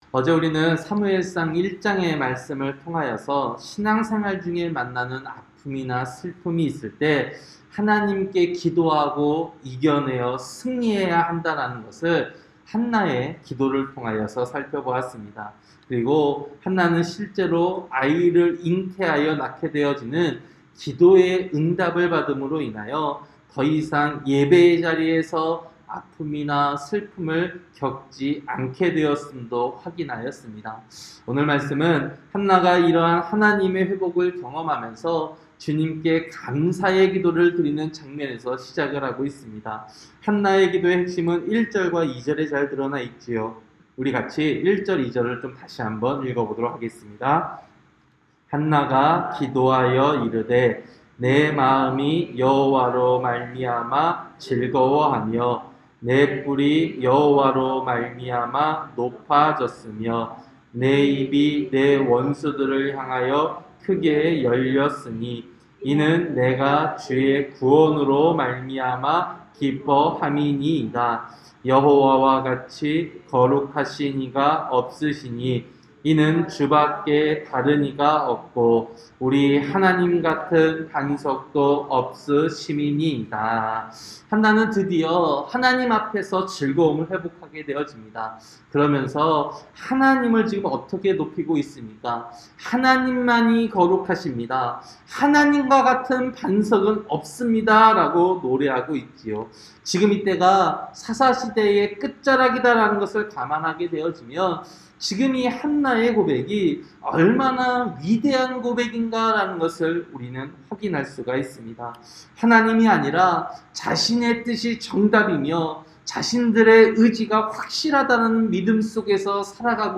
새벽설교-사무엘상 2장